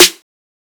snare (energy).wav